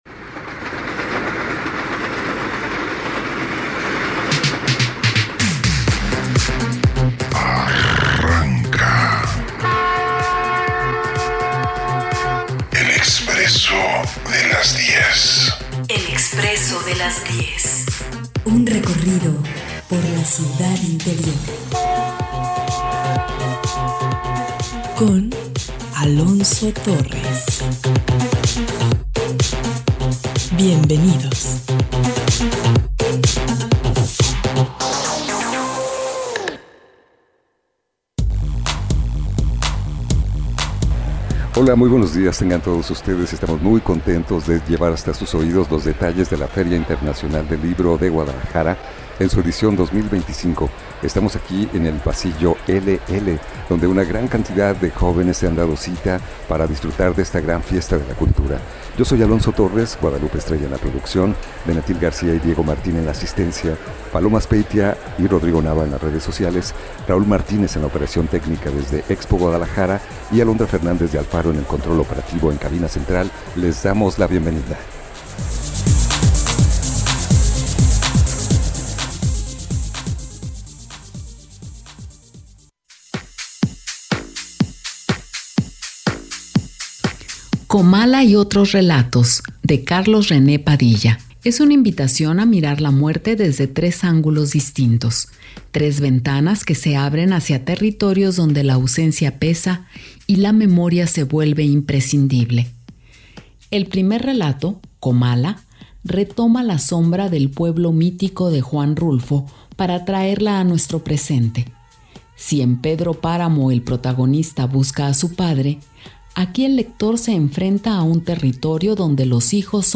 En este podcast de El Expresso de las 10 en nuestro último día de transmisión desde FIL 2025